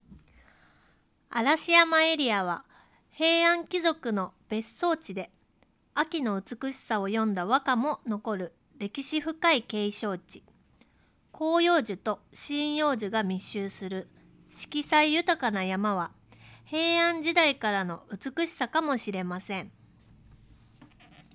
一方、カーディオイド型収音パターンを採用したFIFINE Tank 3で録音した場合は、マイク前方の話者の声のみを綺麗に収音できており、邪魔な環境音やノイズを効果的に低減。非常にクリアなボイスサウンドを録音することができた。
▼FIFINE Tank 3による録音ボイス単体
まるで声優紹介サイトのサンプルボイスのような、明瞭なボイスデータを保存することができた。